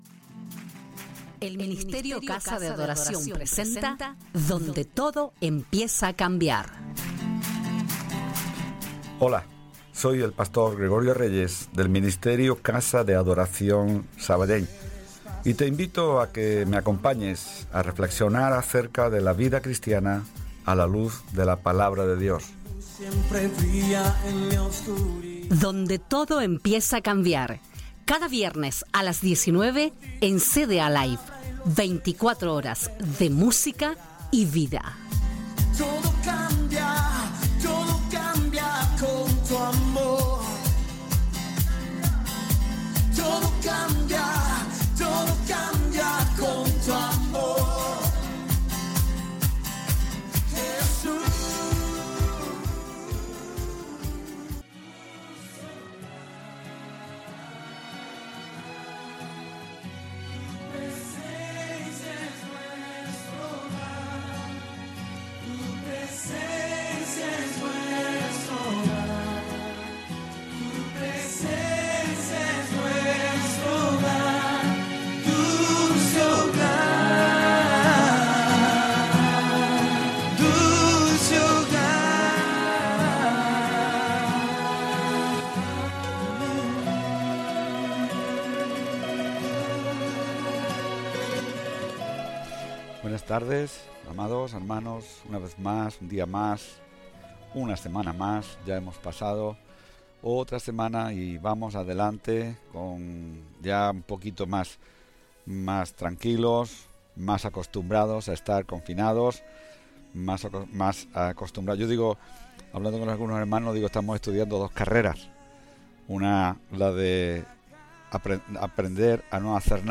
Careta del programa, presentació del programa en un dels dies del confinament degut a la pandèmia de la Covid 19 i sermó a partir de les paraules de Sant Pau